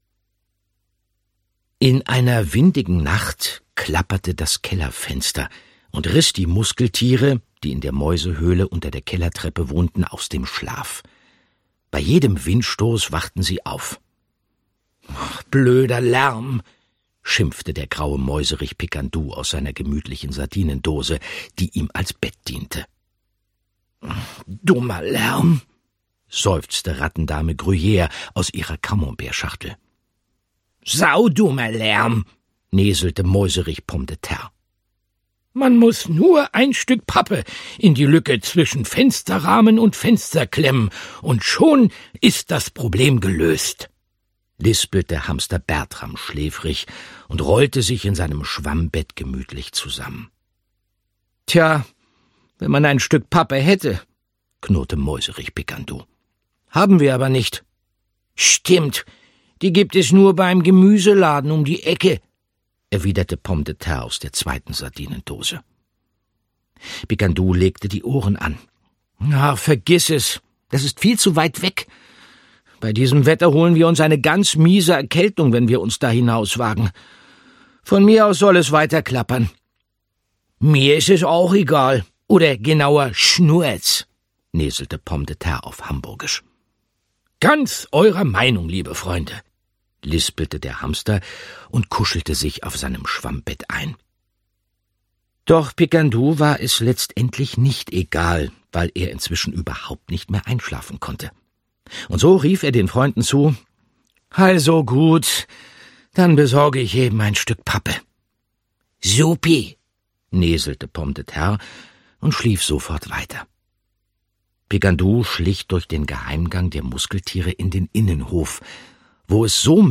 Andreas Fröhlich (Sprecher)
Ungekürzte Lesung, Inszenierte Lesung mit Musik